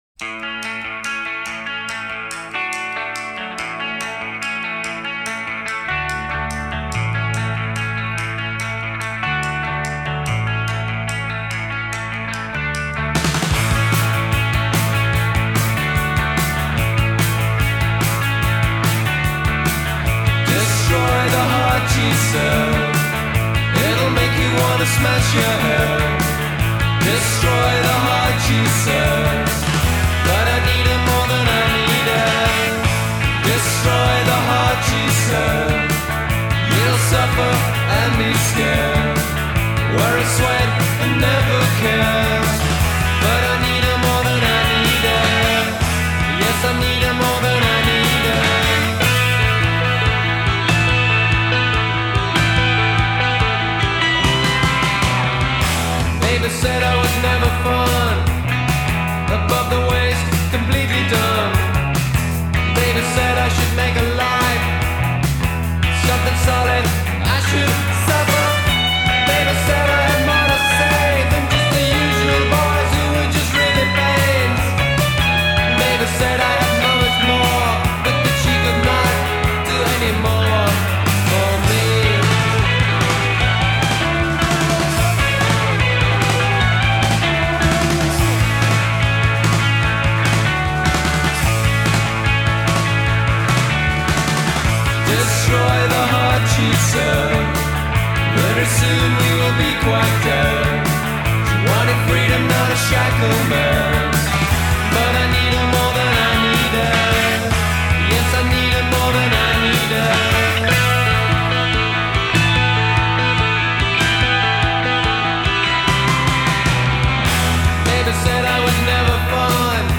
primarily as one of the leading Indie bands of the 1980s.